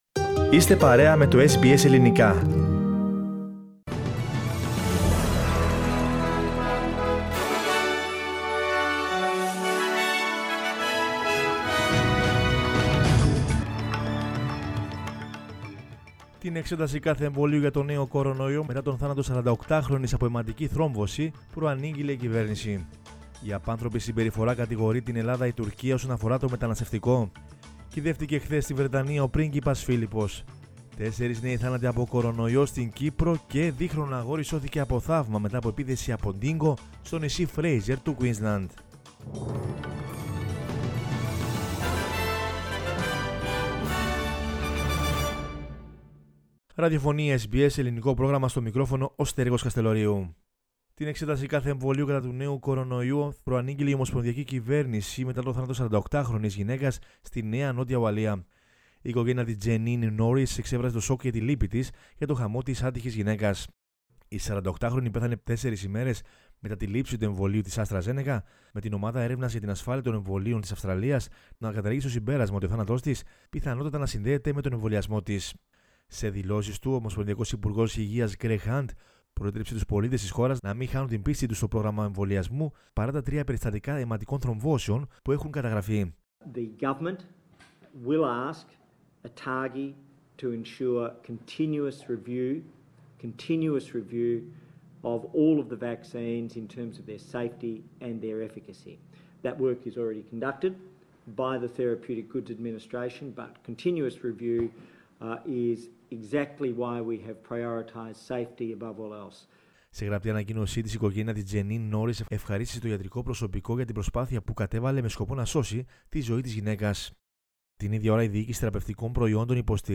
News in Greek from Australia, Greece, Cyprus and the world is the news bulletin of Sunday 18 April 2021.